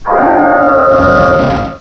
cry_not_reshiram.aif